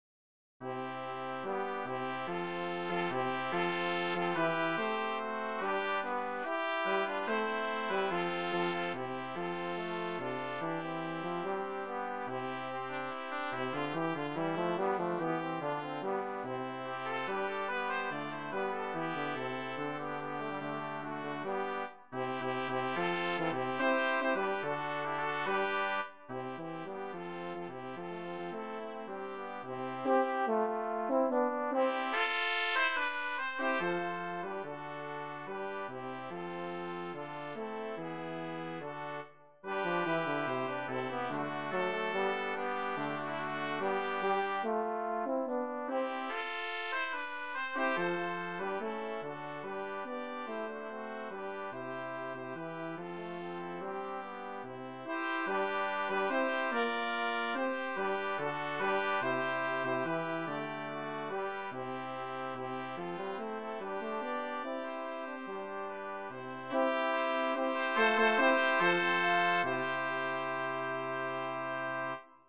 Voicing: Brass Quartet